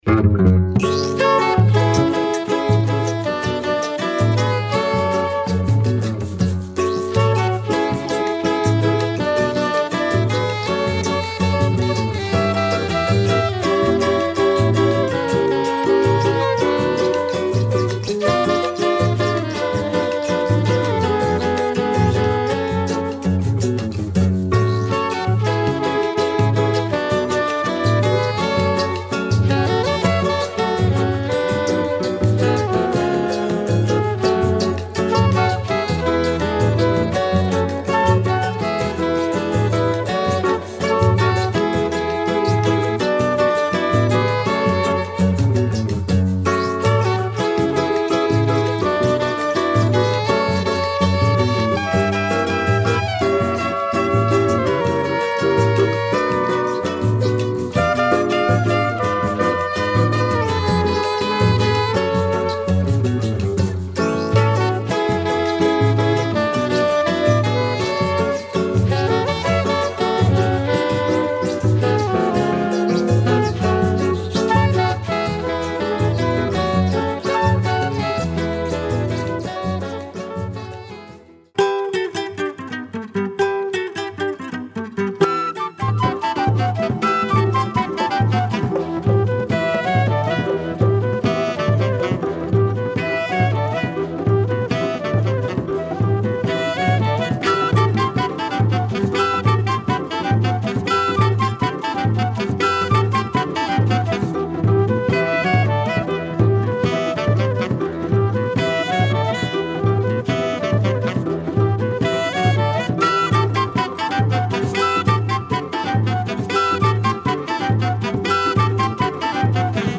saxophonist
guitarist